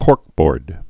(kôrkbôrd)